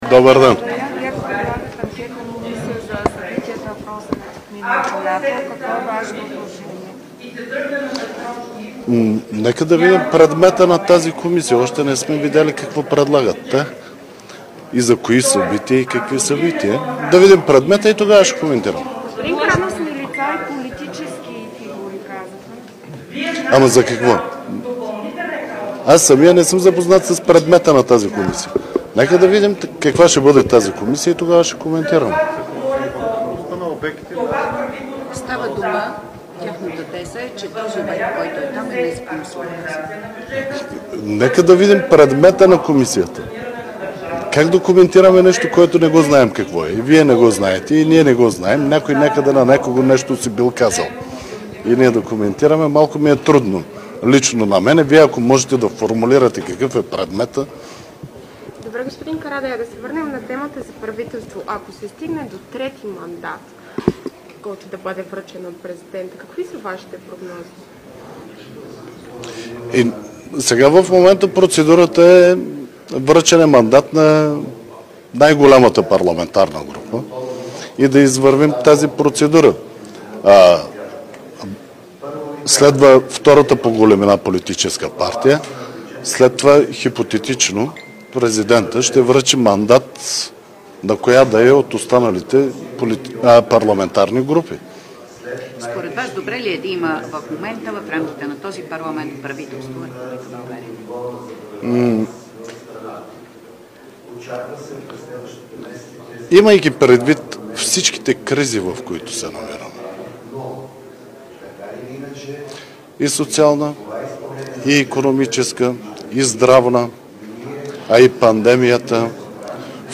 10.30 - Брифинг на председателя на ПГ на ГЕРБ Десислава Атанасова и кандидата на ГЕРБ за министър-председател Даниел Митов и народния представител от ПГ на ГЕРБ Тома Биков. - директно от мястото на събитието (Народното събрание)
Директно от мястото на събитието